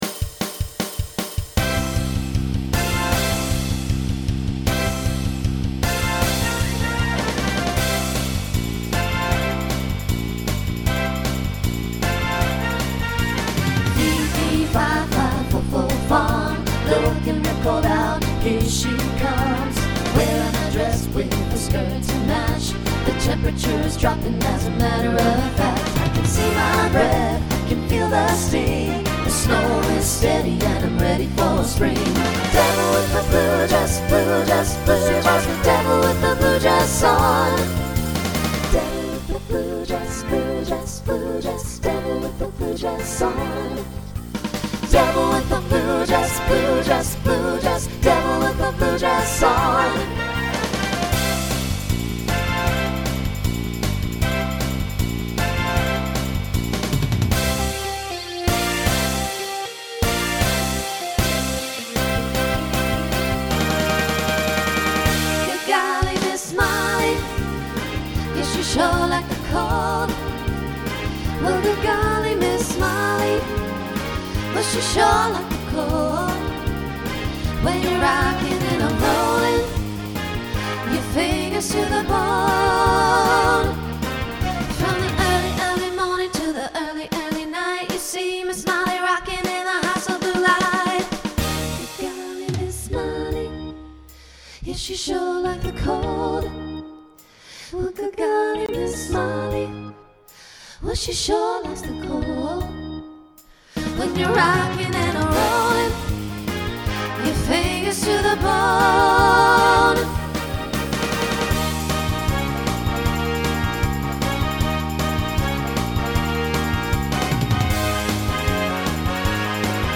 guys/girls feature or SATB stand alone